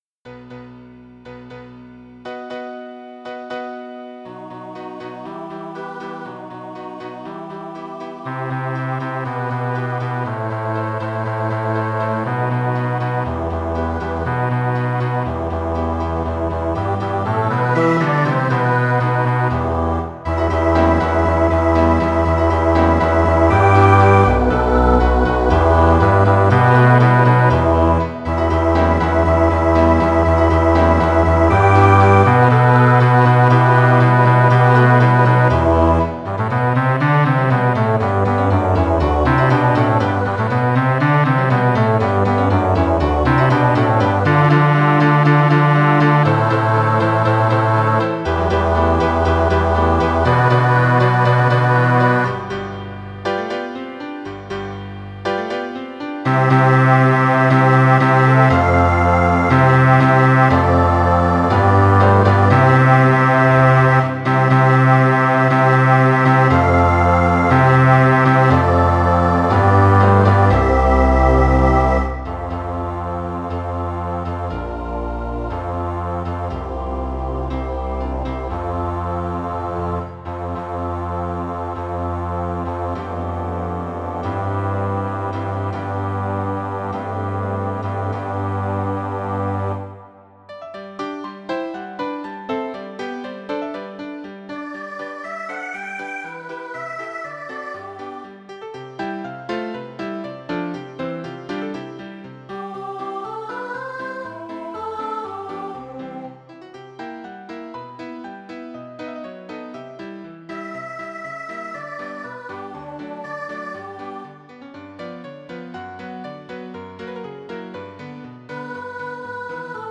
Schumann-Practice-Bass.mp3